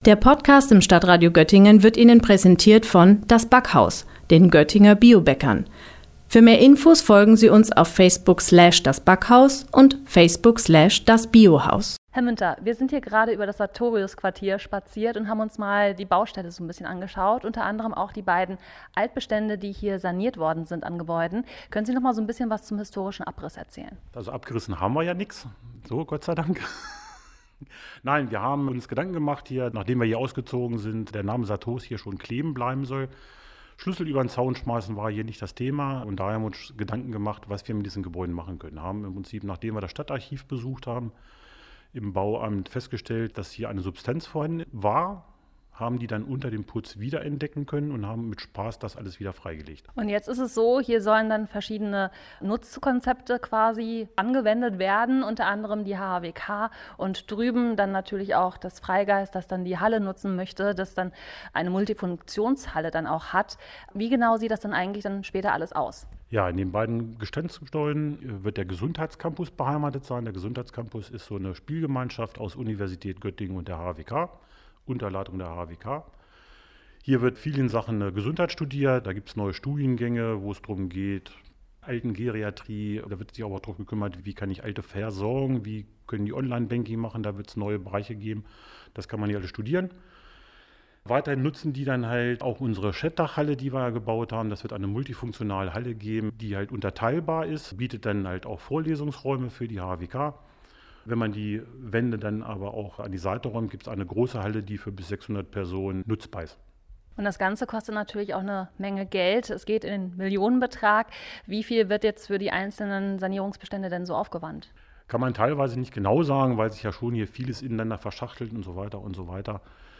Bohrmaschinen, Sägen, Hämmern – der Baulärm am Sartorius Quartier in Göttingen macht deutlich, dass hier fleißig gewerkelt wird.